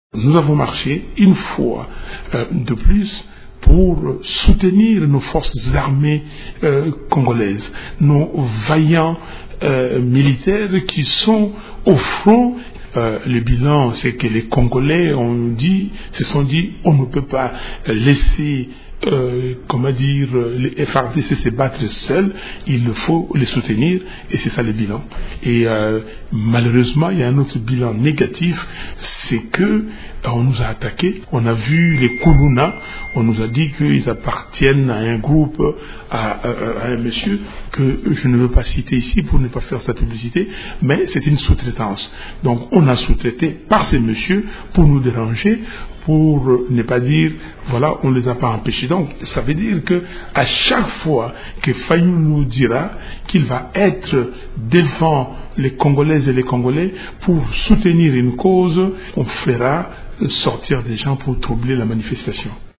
Il s’entretient avec